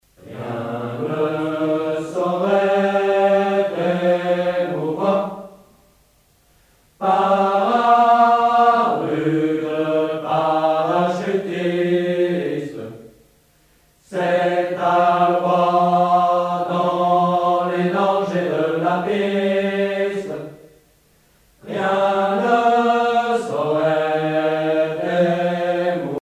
Usage d'après l'analyste circonstance : militaire
Pièce musicale éditée